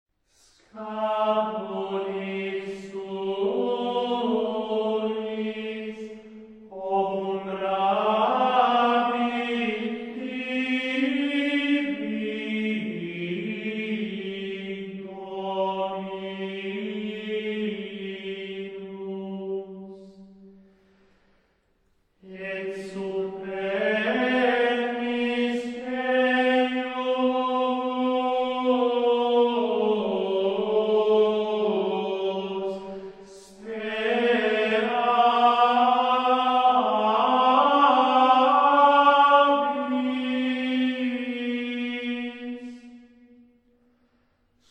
Григорианские хоралы Великого Поста в исполнении ансамбля "Cantori Gregoriani".